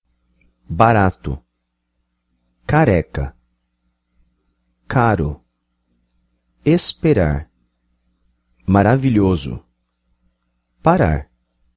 4. If the r comes between two vowels it sounds like a flap d. The same happens when there is a consonant + r sound.